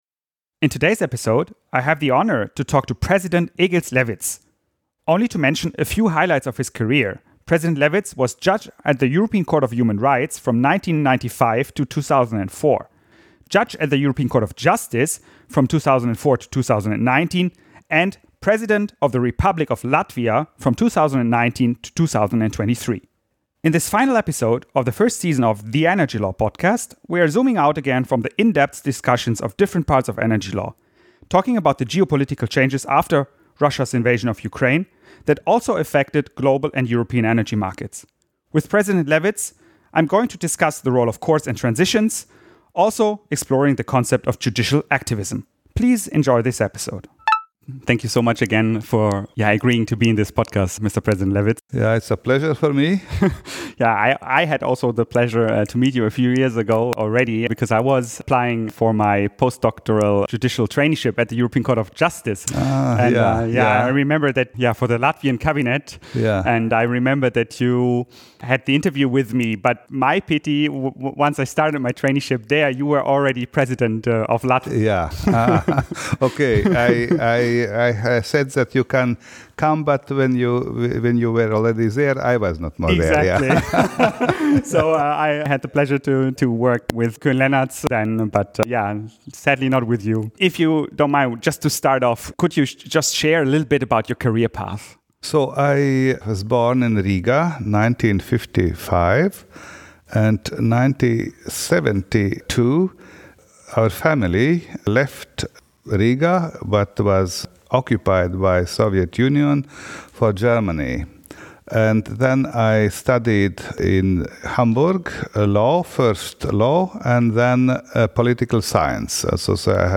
The interview took place on 1 June 2024.